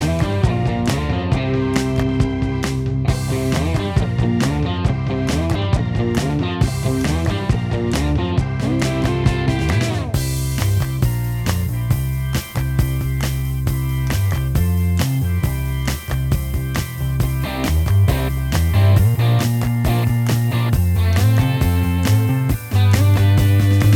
Minus High Lead Guitar Soft Rock 2:25 Buy £1.50